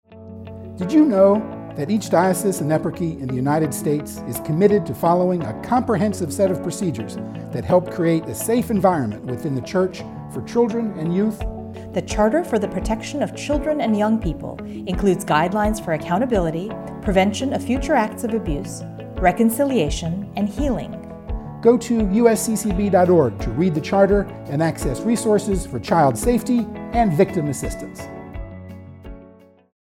One of two 30 second audio pieces for use as PSA radio spots to commemorate the 20th anniversary of the Charter for the Protection of Children and Young People.